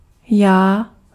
Ääntäminen
US : IPA : [mi]